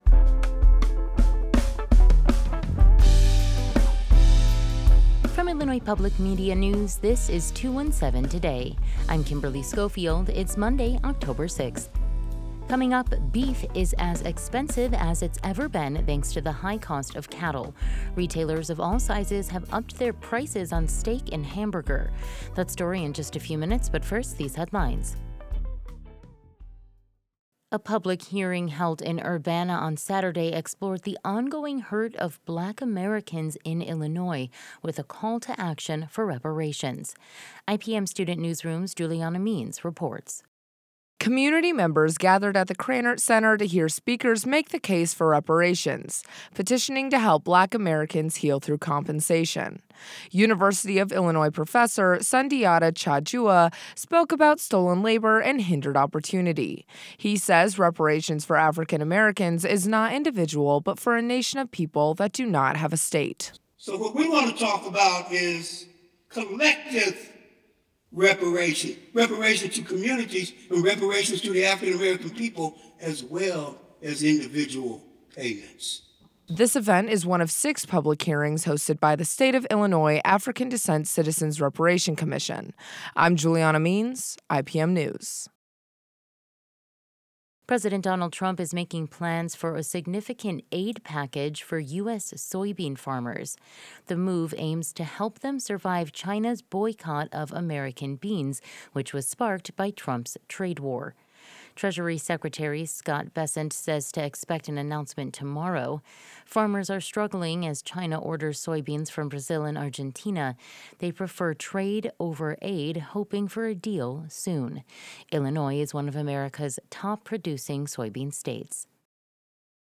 217 Today: When will beef prices drop? We asked a rancher, a butcher and an economist